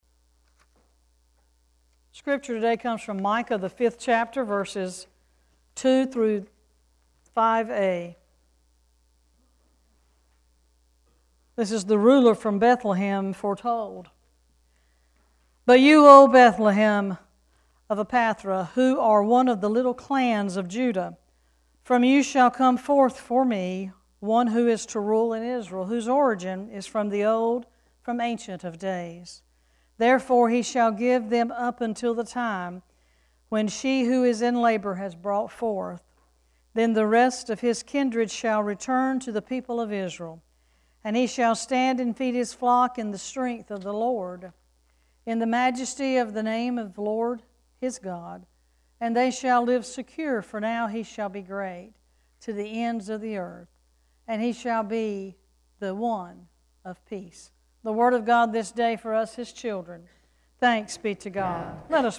This weeks scripture and message: Scripture: Micah 5:2-5a Message